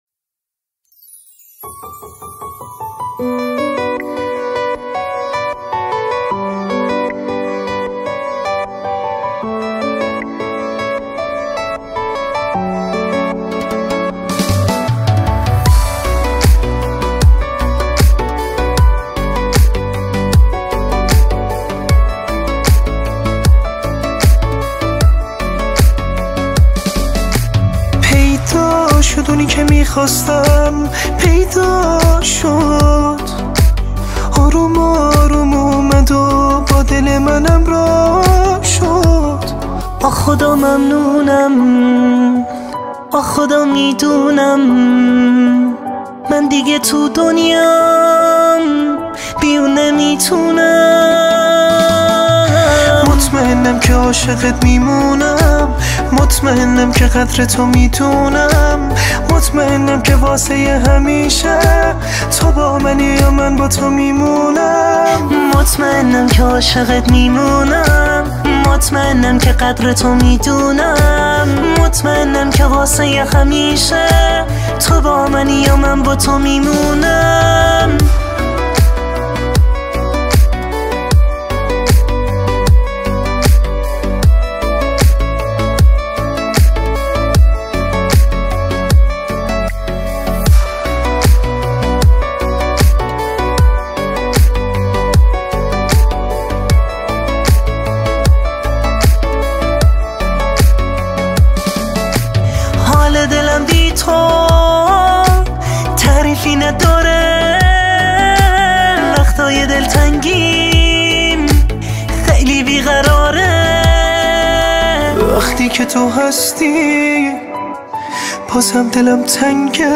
Категория: Иранские